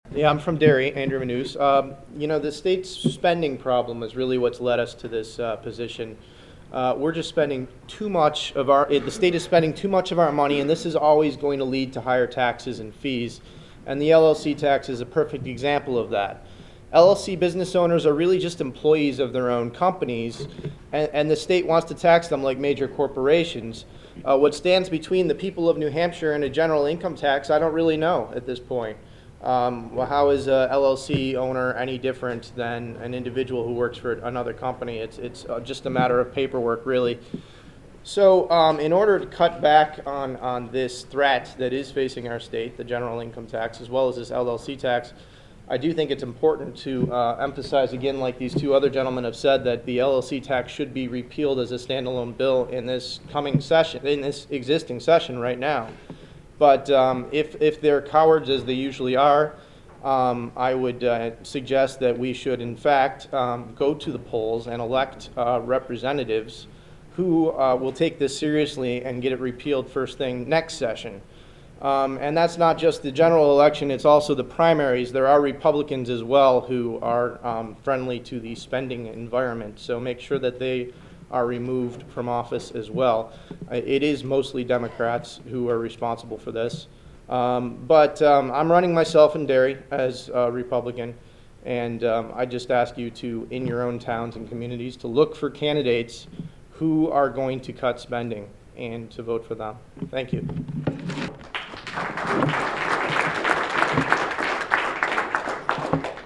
The following audio cuts are soundbytes from the 6/8/10 LLC Tax press conference at the State House and aired on the show: